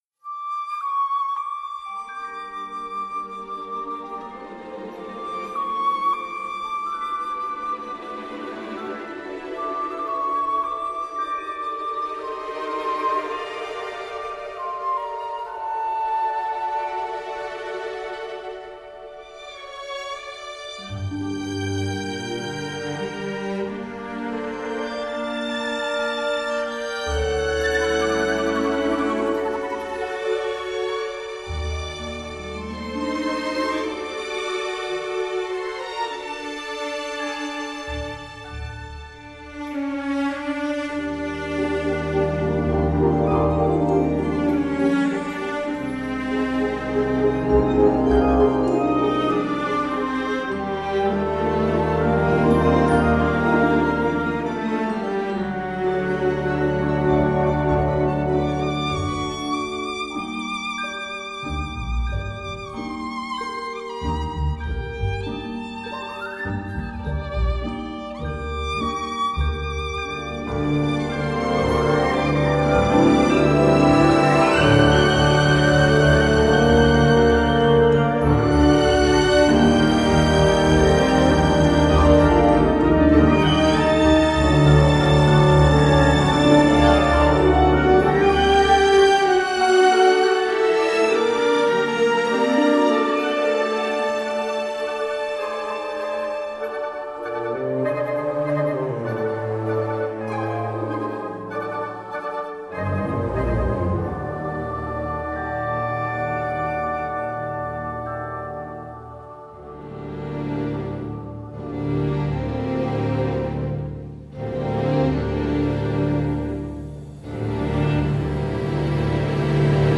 La musique, bruitiste, ne fait jamais peur